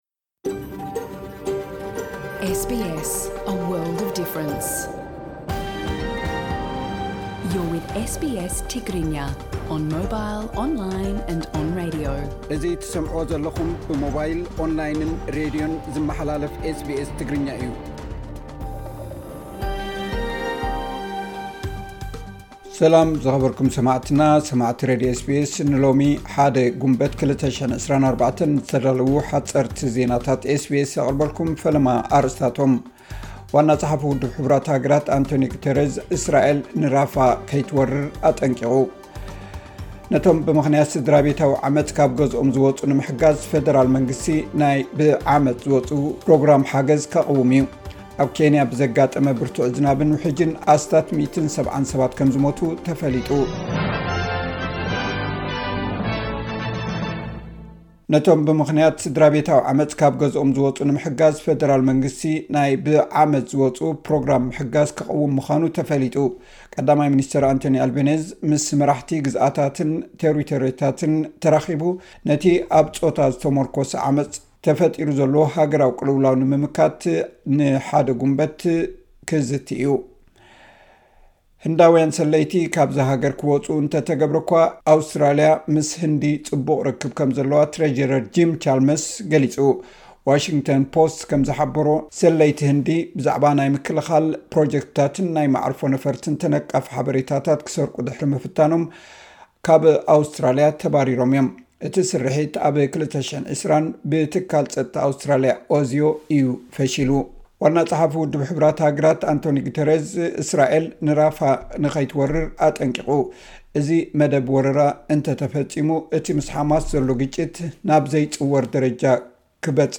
ሓጸርቲ ዜናታት ኤስ ቢ ኤስ ትግርኛ (01 ግንቦት 2024)